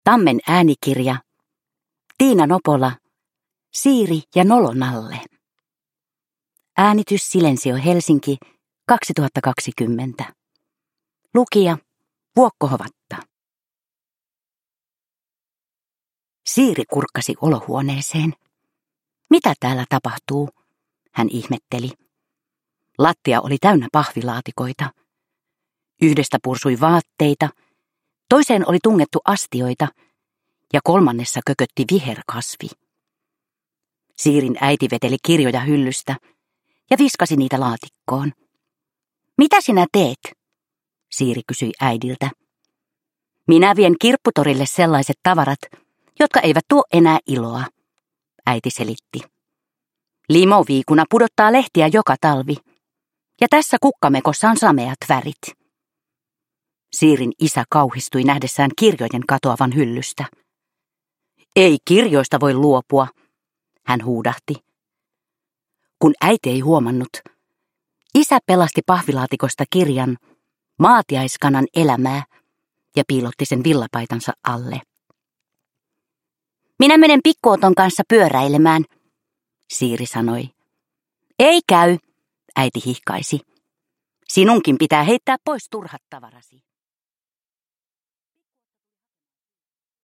Siiri ja nolo nalle – Ljudbok – Laddas ner